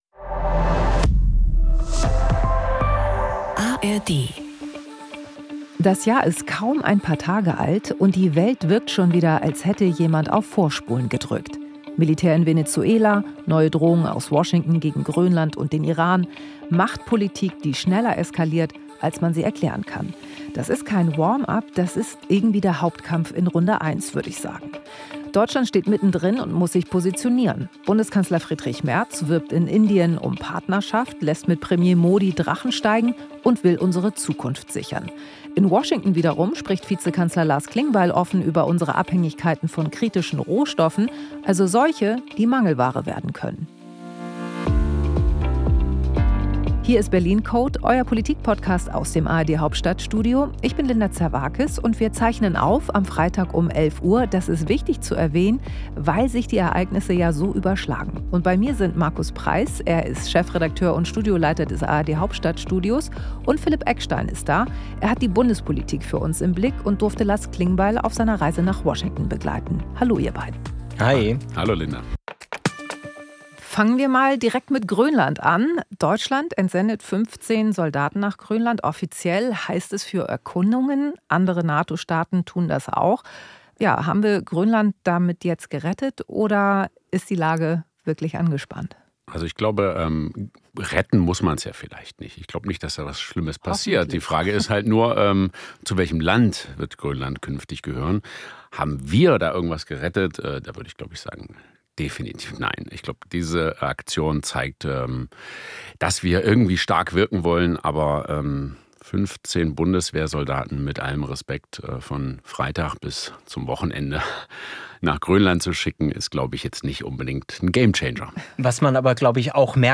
Im Berlin Code spricht Linda Zervakis darüber, wie Deutschland seine Partner weltweit sucht.